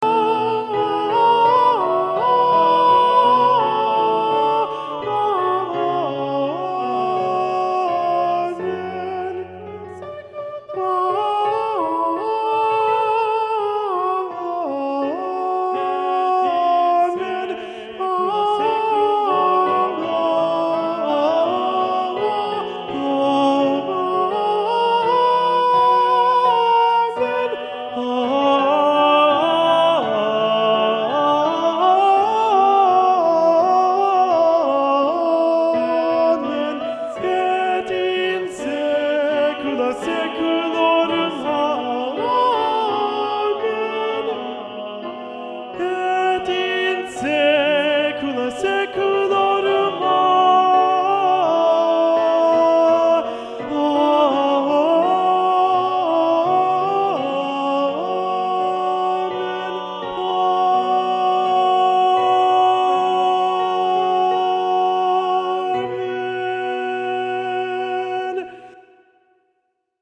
Alto learning track
domine_alto.mp3